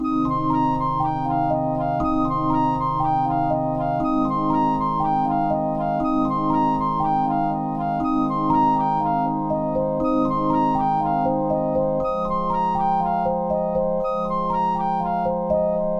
美丽的早晨 电影或嘻哈的钟声 120 BPM
描述：电影或嘻哈的铃声长笛和塞莱斯塔
Tag: 120 bpm Hip Hop Loops Bells Loops 2.69 MB wav Key : Unknown